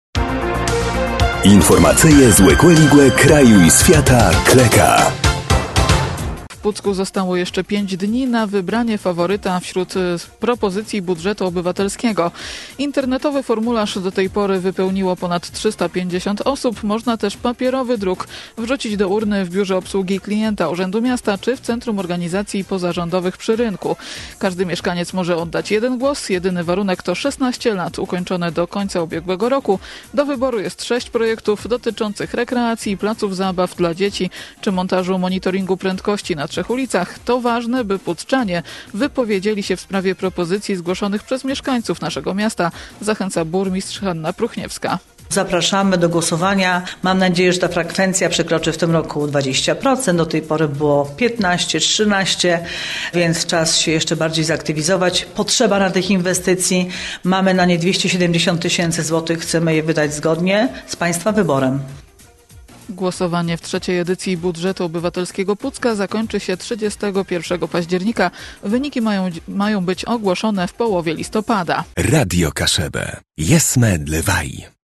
– To ważne, by Pucczanie wypowiedzieli się w sprawie propozycji zgłoszonych przez mieszkańców naszego miasta – zachęca burmistrz Hanna Pruchniewska.